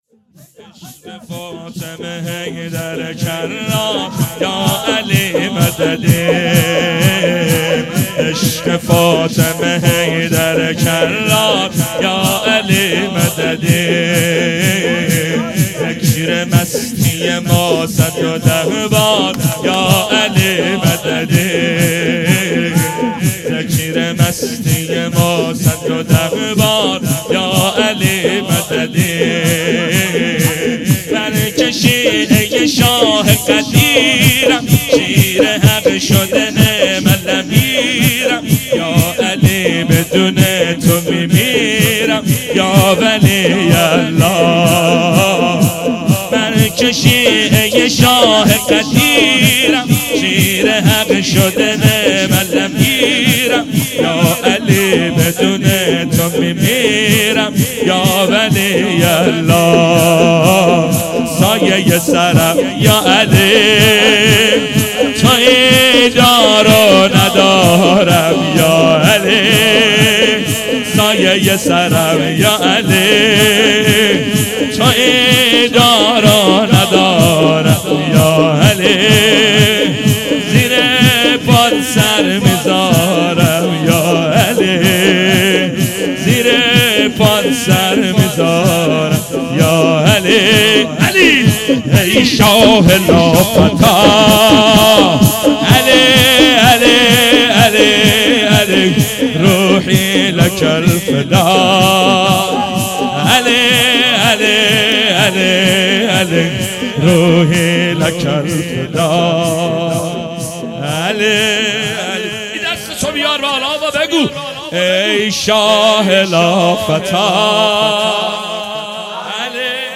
شور | عشق فاطمه حیدر کرار